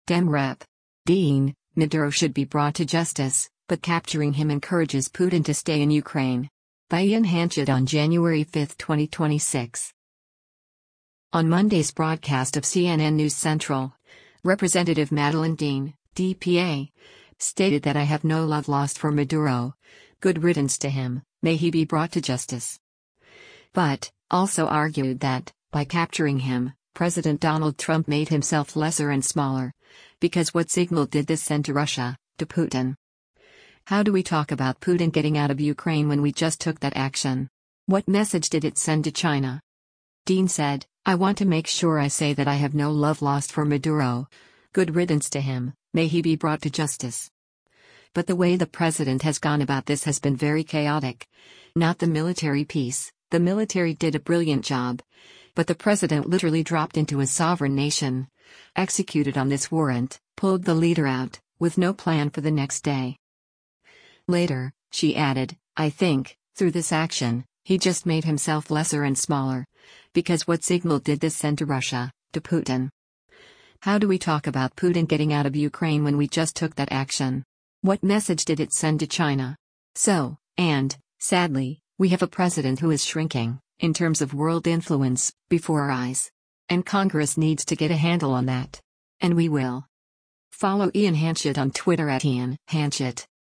On Monday’s broadcast of “CNN News Central,” Rep. Madeleine Dean (D-PA) stated that “I have no love lost for Maduro, good riddance to him, may he be brought to justice.”